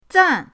zan4.mp3